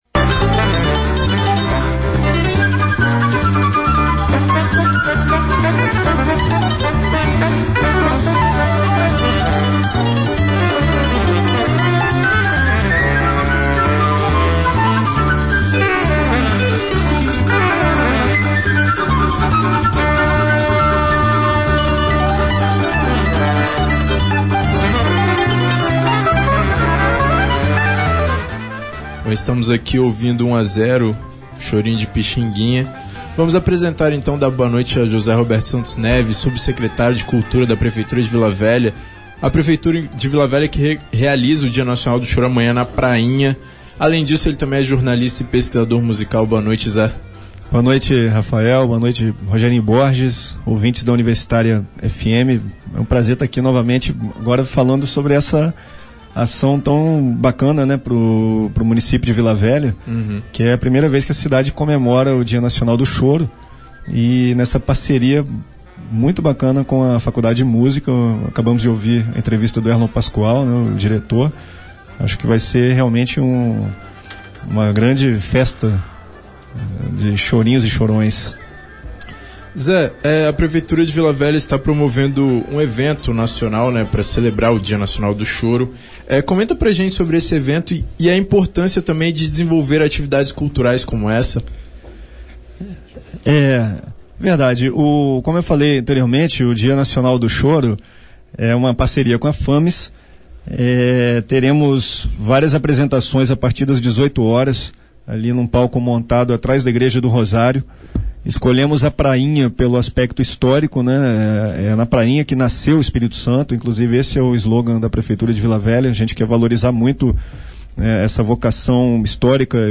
Ouça o bate-papo na íntegra: Especial Dia Nacional do Choro Download : Especial Dia Nacional do Choro